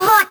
VEC3 Percussion 032.wav